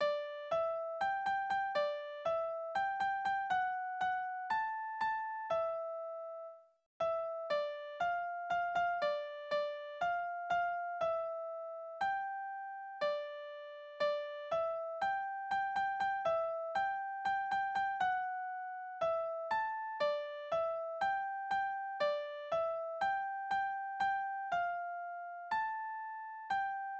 Kinderlieder: Bub und Spinne
Tonart: G-Dur
Taktart: 4/4
Tonumfang: Quinte
Besetzung: vokal
Anmerkung: die Textverteilung ist fragwürdig die Takte sind unterschiedlich lang die Tonart ist nicht eindeutig